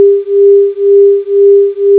Figure 1 shows an example waveform of such a summation using sine waves of 399 and 401Hz.
AM Tone (Sun .au format)
As can be seen the 'beating' effect on the envelope of the resultant waveform is both distinct and regular.
Therefore if we take our previous example, the resultant waveform has a frequency of 400Hz, and the frequency of the envelope beating is 2Hz.